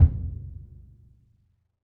BDrumNewhit_v3_rr2_Sum.wav